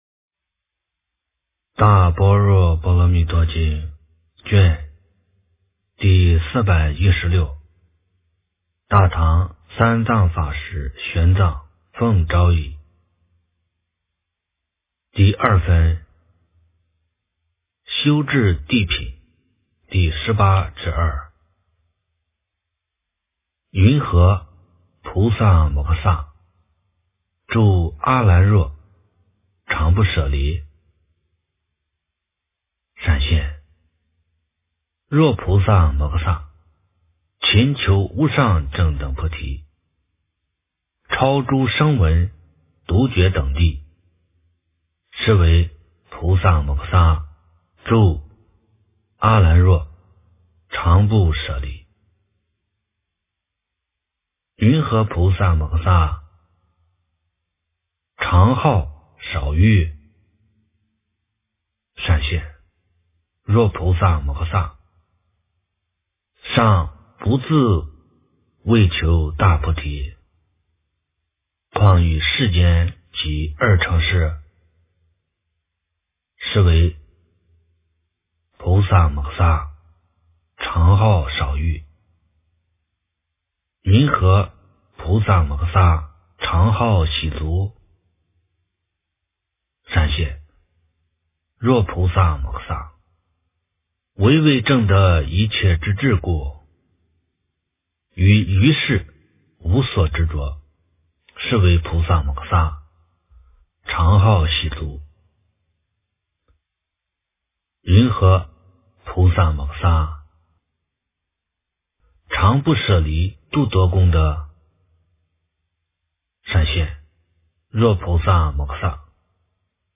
大般若波罗蜜多经第416卷 - 诵经 - 云佛论坛